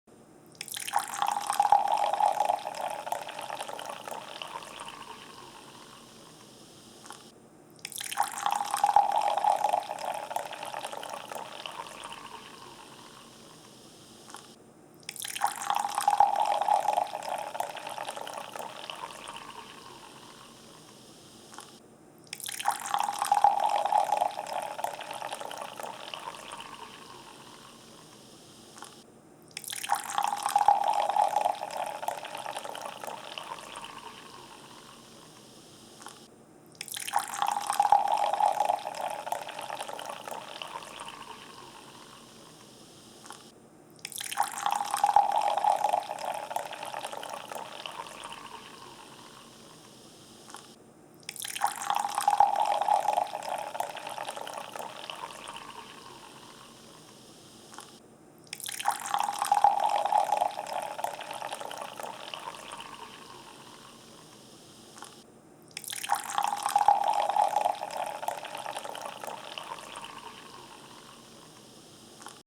Aider les parents à faire faire pipi à bébé (sons d'eau) (256 kbps) 38566
• Catégorie: Bruits d'eau pour faire pipi 1195